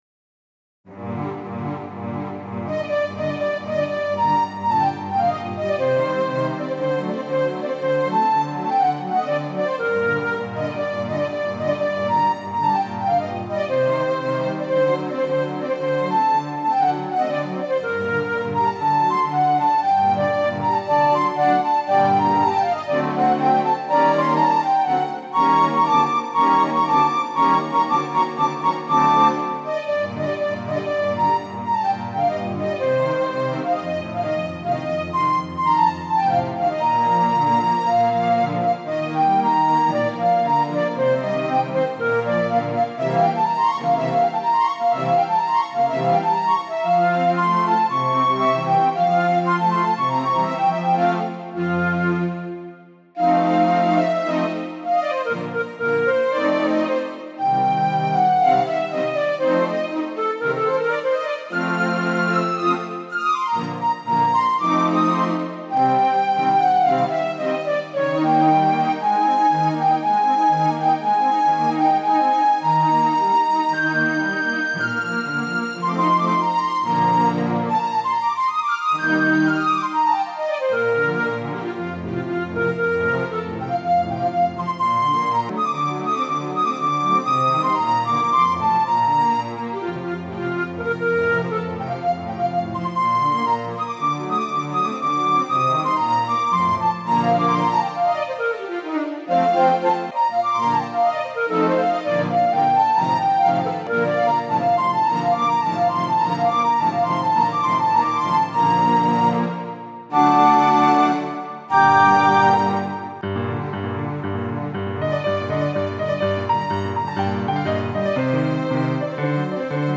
The piano line anchors it with clarity, while the flute and brass colors lift it above the earth. At times it feels like breath itself, pulsing and pulling, but then it turns introspective, almost like a conversation with memory.
It makes the listener feel alert, awake, and yet oddly soothed.
The piano opens the dialogue with clipped urgency, not mournful but searching — a mind pacing.
The brass enters like a stern voice, a reminder of the world’s weight, grounding the fluttering flight of the flute.
mozart-symphony-40-flute-piano-brass-versions.mp3